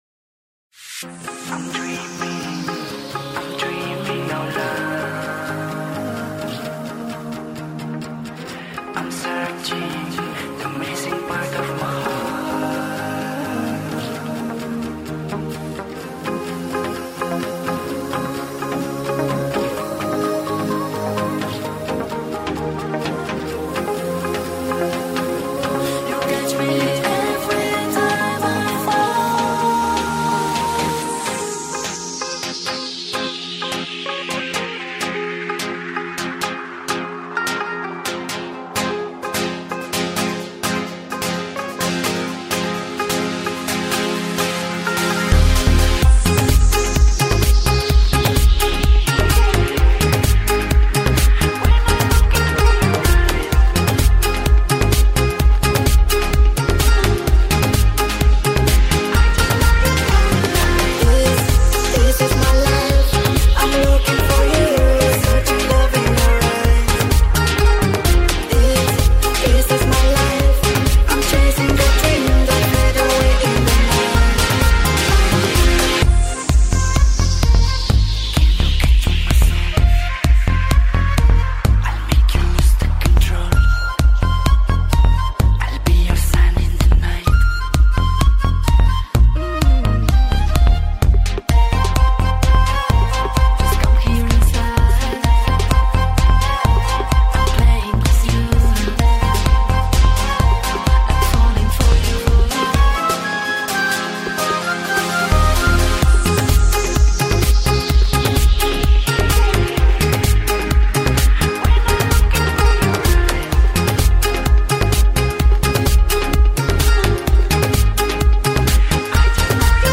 Mix Hot